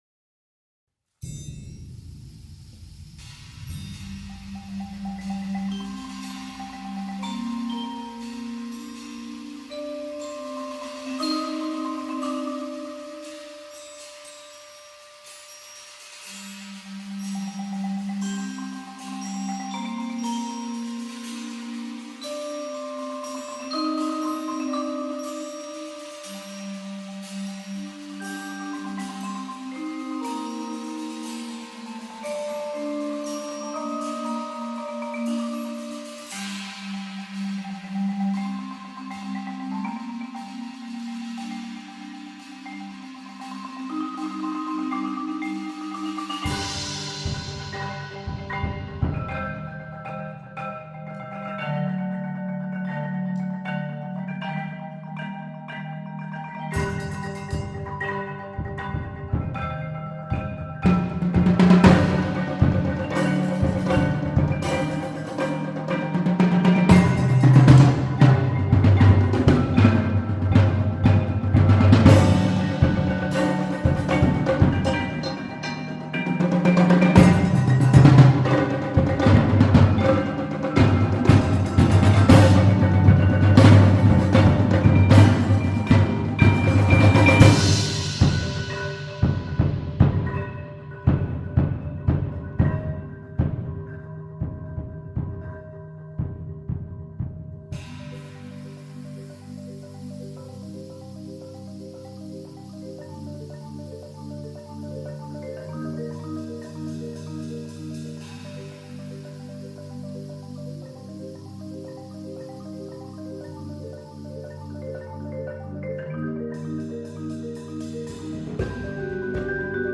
Voicing: Percussion Octet